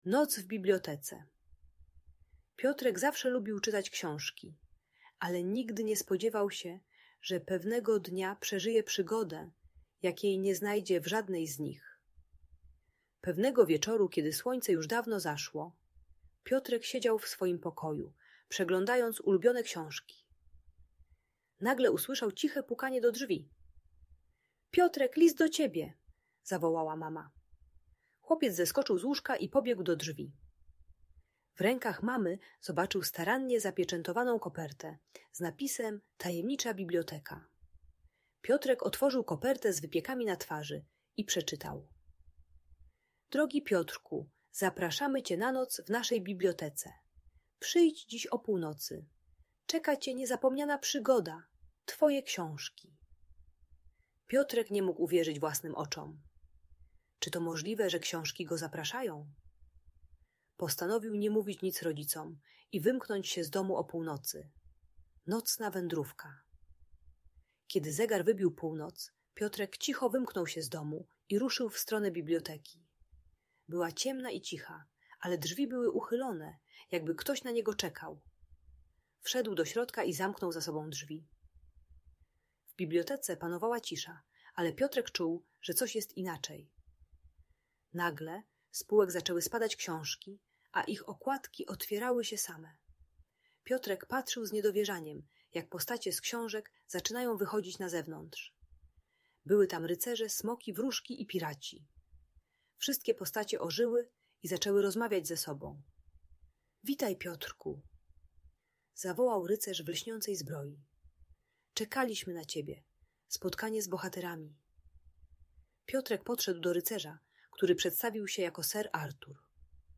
Noc w Bibliotece - Audiobajka